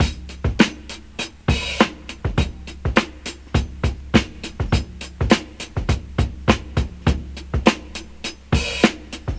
• 102 Bpm High Quality Rock Breakbeat C# Key.wav
Free drum groove - kick tuned to the C# note. Loudest frequency: 1708Hz
102-bpm-high-quality-rock-breakbeat-c-sharp-key-fOD.wav